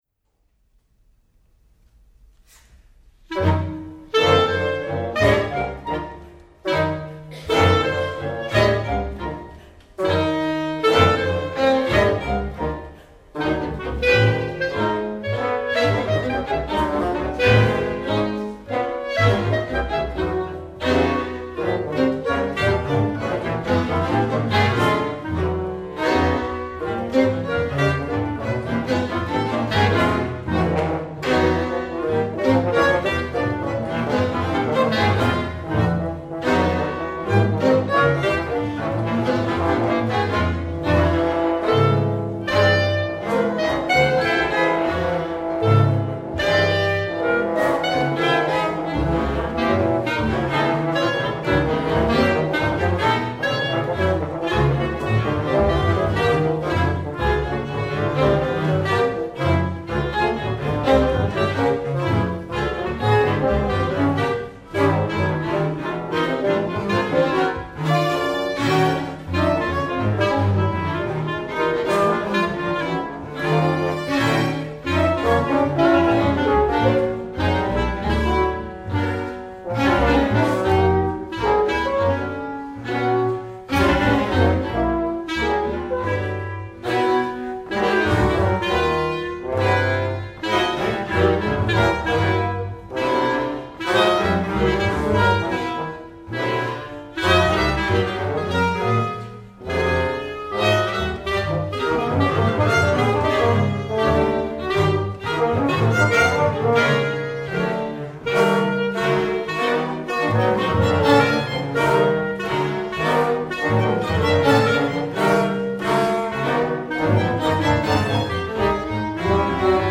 for Octet